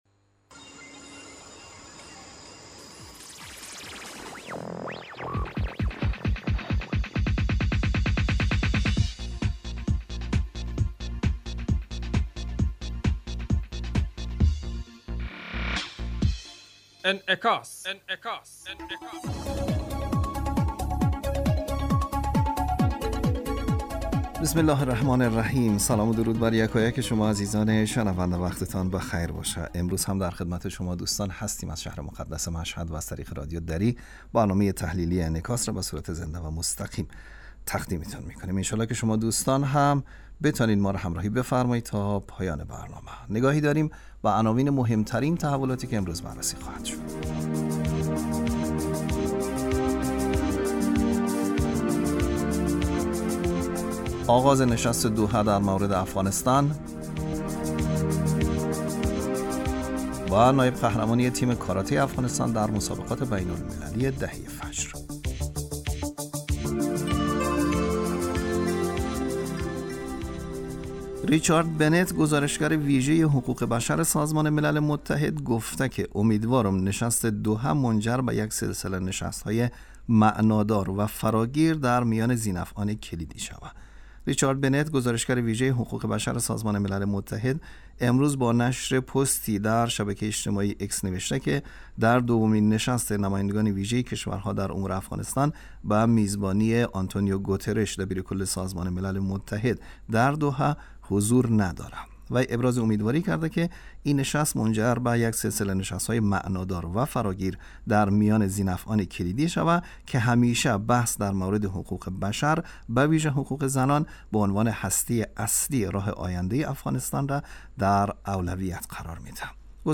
برنامه انعکاس به مدت 35 دقیقه هر روز در ساعت 06:50 بعد از ظهر (به وقت افغانستان) بصورت زنده پخش می شود. این برنامه به انعکاس رویدادهای سیاسی، فرهنگی، اقتصادی و اجتماعی مربوط به افغانستان، برخی از خبرهای مهم جهان و تحلیل این رویدادها می پردازد.